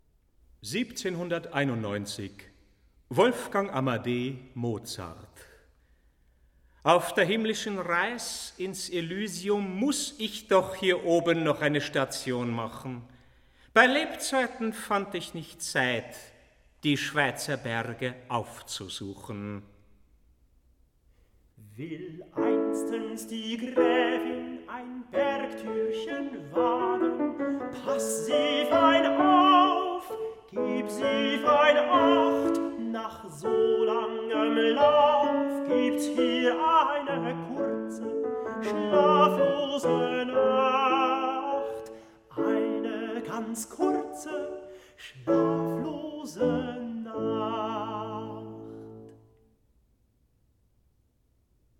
Tenor, piano